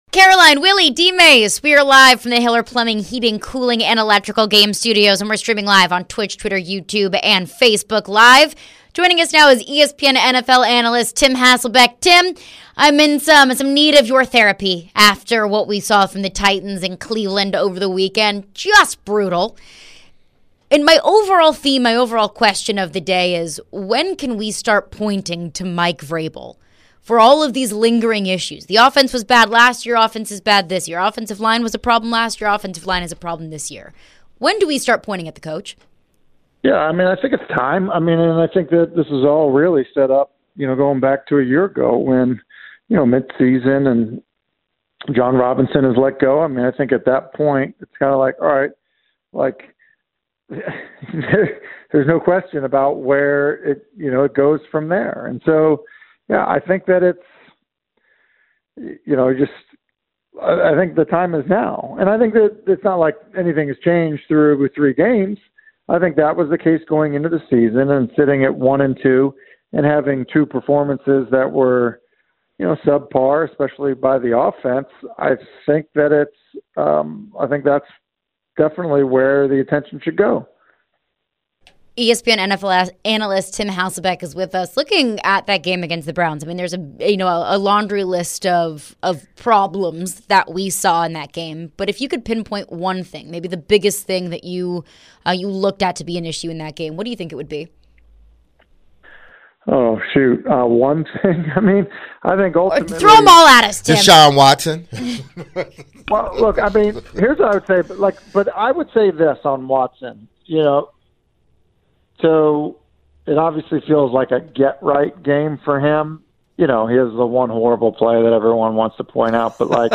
joined by ESPN's Tim Hasselbeck who answered a few questions about the Titans offense, Mike Vrabel's job security, and much more. Is Mike Vrabel on the hot seat? Should Titans fans be worried?